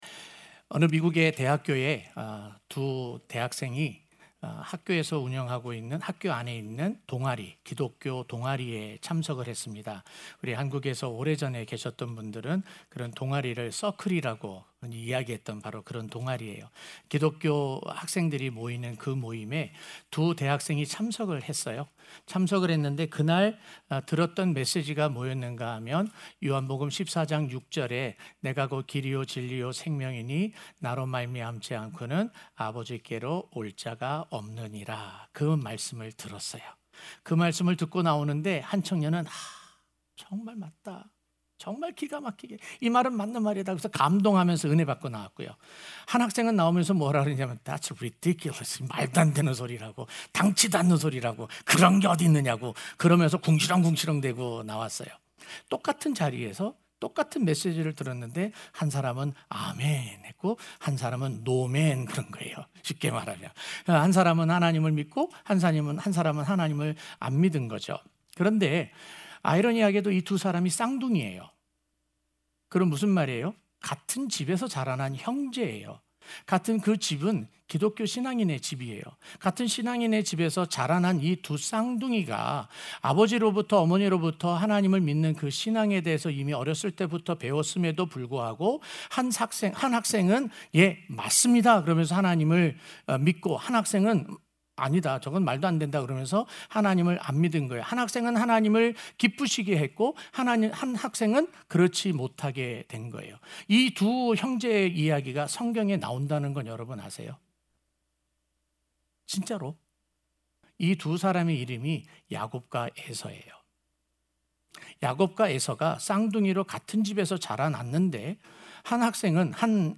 04-A-Irresistible-Grace-1-Sunday-Morning.mp3